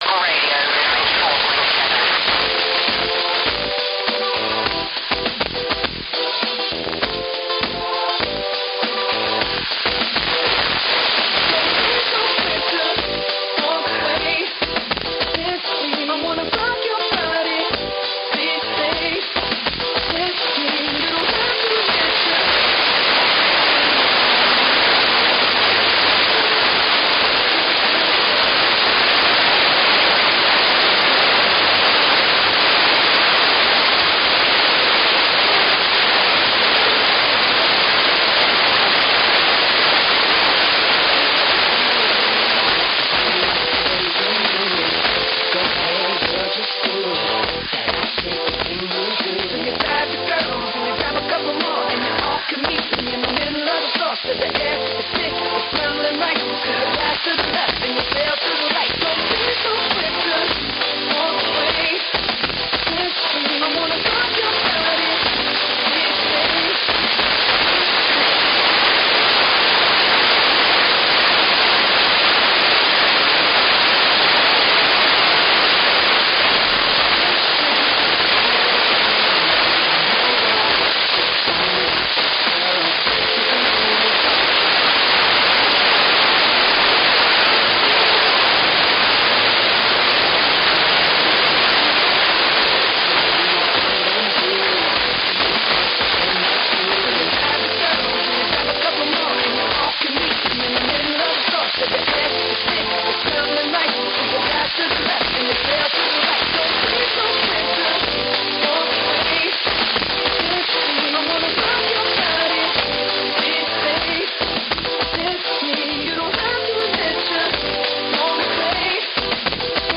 Két érdekes vétel, az első a 48,425 MHz-ről:
- ID (3'26"): "Maldon Districts True Local Radio Saint FM" - pontos idő (3'33"): "half past four" - UTC 15:30-kor. Mi lehet ez? - legvalószínűbb, hogy egy közösségi rádió stúdió és adóállomás közötti átjátszása Angliából.